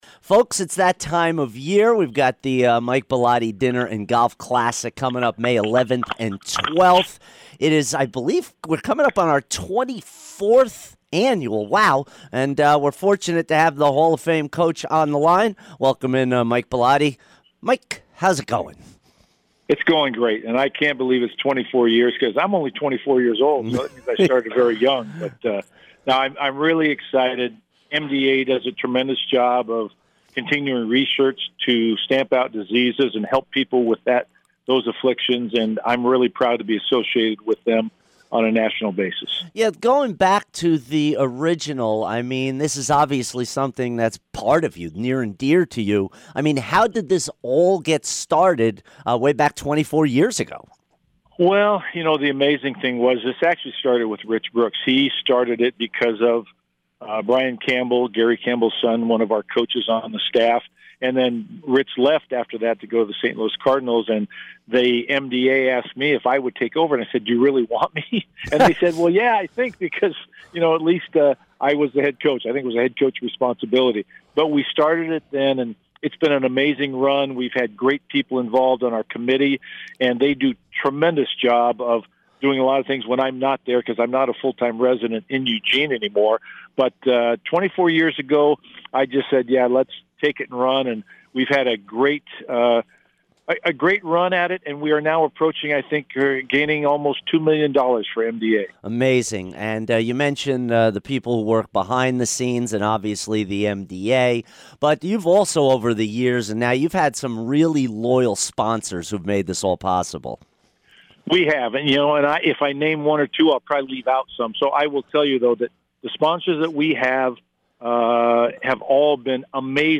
Mike Bellotti Interview 4-19-17